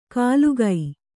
♪ kālugai